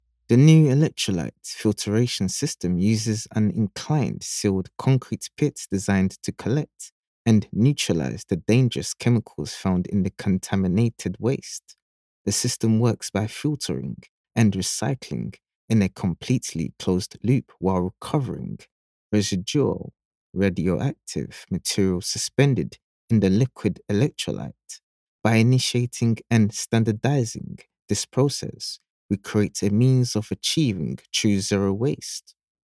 Never any Artificial Voices used, unlike other sites.
E=learning, Corporate & Industrial Voice Overs
English (Caribbean)
Adult (30-50) | Yng Adult (18-29)